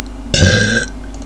burp.wav